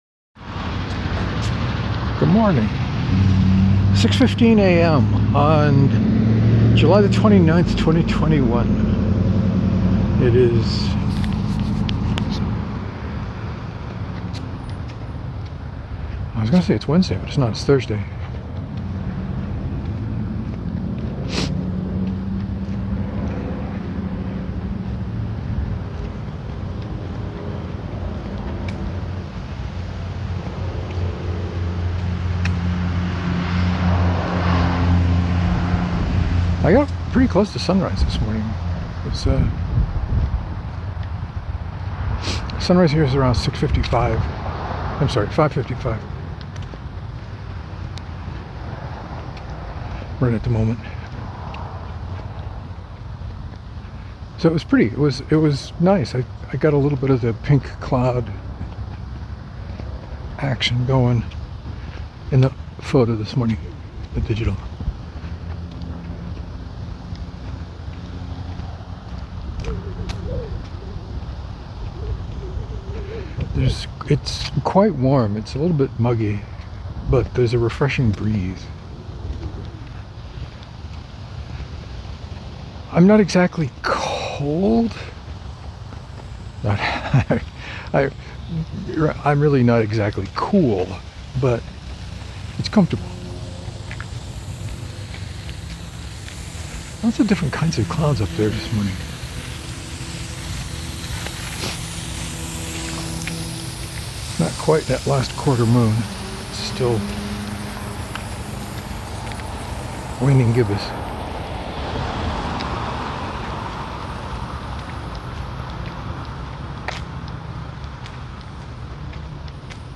The wind noise toward the end was pretty egregious. I need to find a better/newer wind baffle.